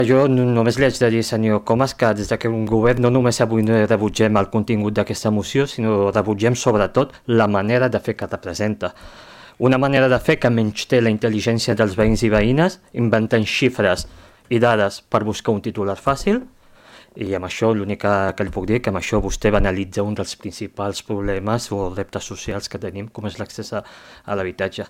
El ple municipal de Calella va viure un debat intens sobre la situació de l’habitatge arran d’una moció presentada per la CUP per completar el recompte d’habitatges buits i activar mesures municipals d’intervenció.
En la seva rèplica final, Laroussi va reiterar el rebuig del govern a la moció, tant pel contingut com per la forma.